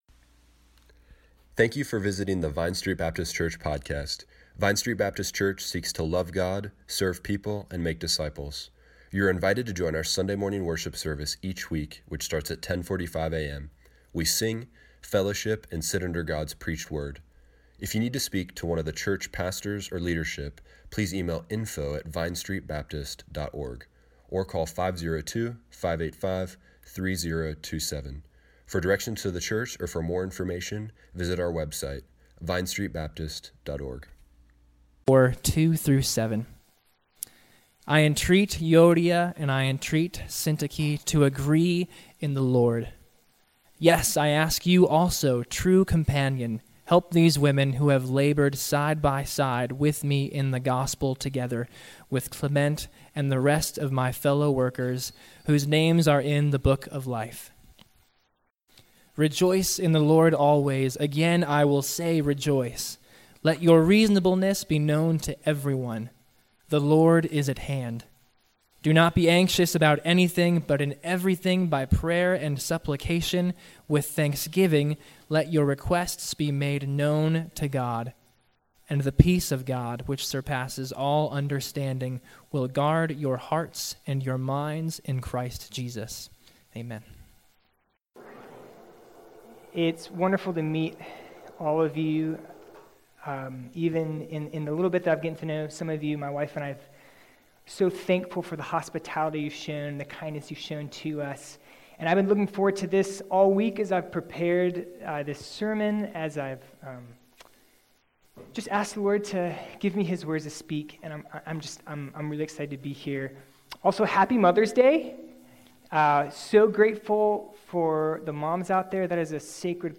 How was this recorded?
A Study in Unity Service Morning Worship Tweet Summary May 12